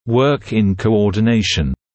[wɜːk ɪn kəuˌɔːdɪ’neɪʃn][уёк ин коуˌоːди’нэйшн]слаженно работать, работать в координации